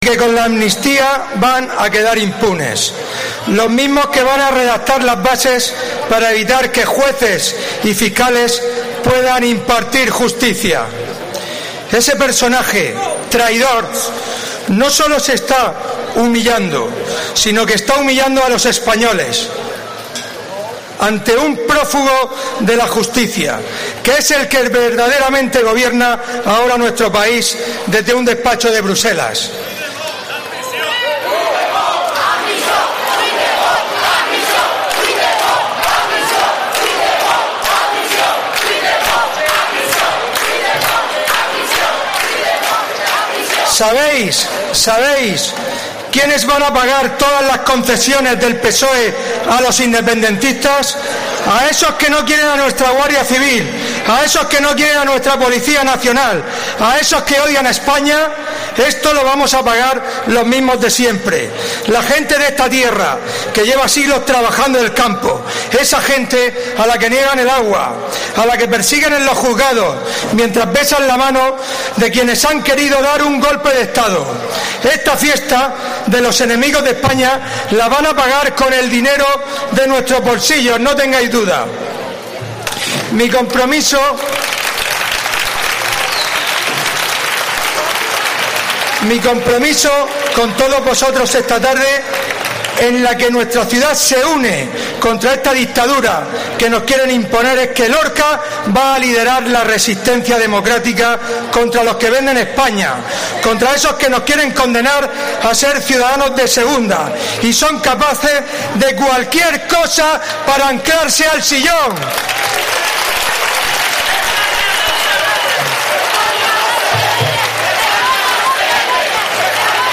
DISCURSO PENCHO GIL
El PP de Lorca llenó el martes por la noche la plaza de Calderón de la Barca, en pleno centro de la ciudad, en un multitudinaria protesta contra la Ley de Amnistía, a la que asistieron 2.000 personas según el balance de la Policía Local, que la Policía Nacional rebajó a solo 600.
La única intervención fue la del presidente local del partido y alcalde de Lorca, Fulgencio Gil, que pronunció un encendido discurso en defensa de la democracia y de la igualdad de los españoles, frente a los que traicionan esos principios “por solo siete votos”, en referencia a Sánchez.